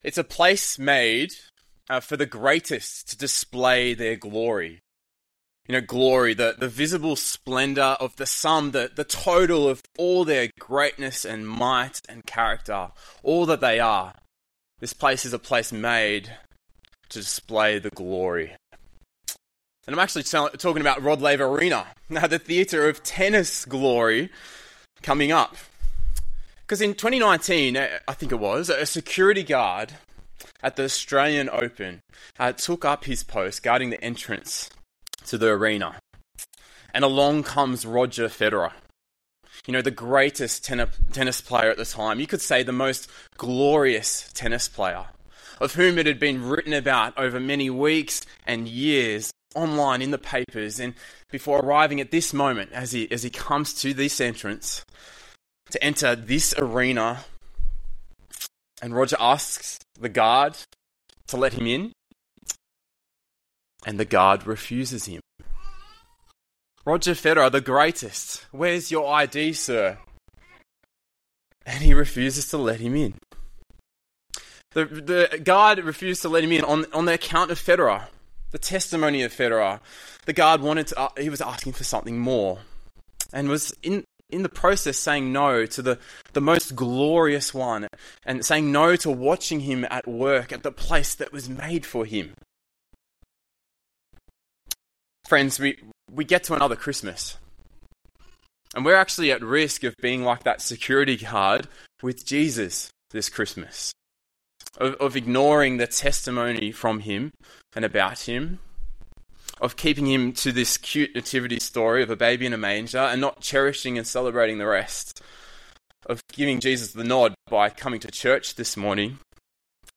One-Off Sermons